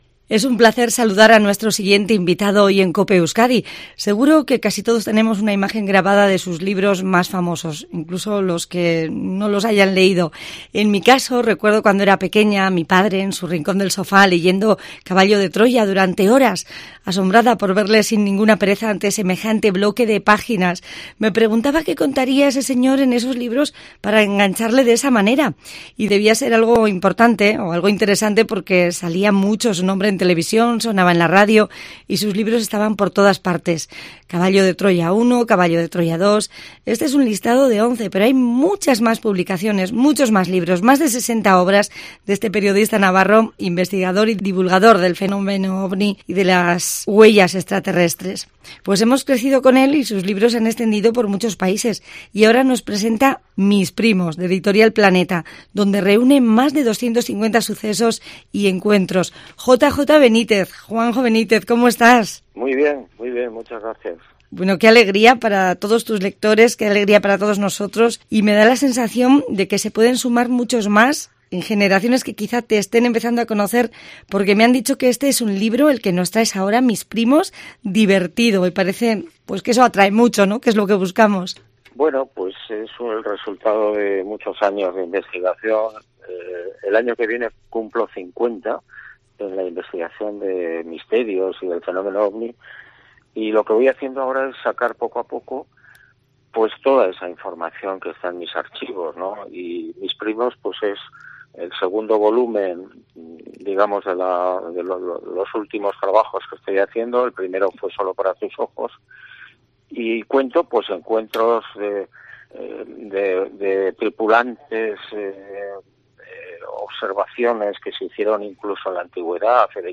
Le hemos preguntado acerca del asteroide que ha cometado que podría caer en la Tierra en 2027, no te pierdas su respuesta a esa y otras preguntas escuchando la entrevista completa clickando junto a la imagen.